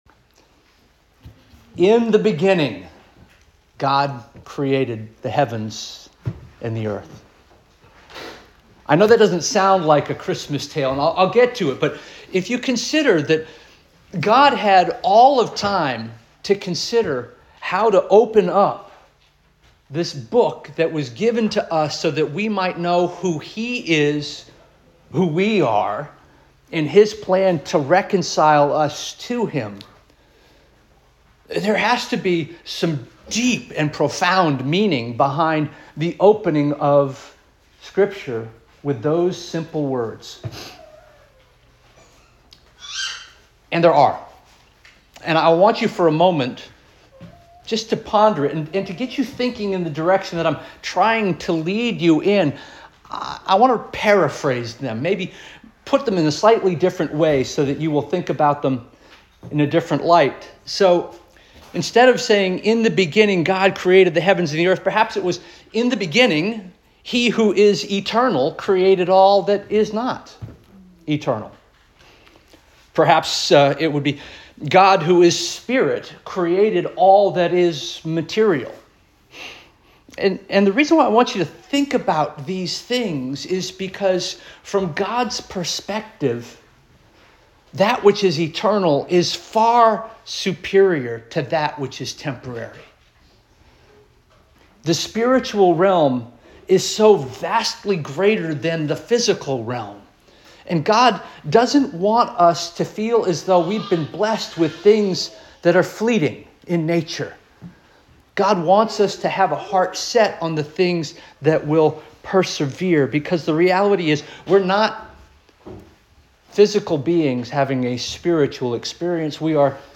December 21 2025 Sermon - First Union African Baptist Church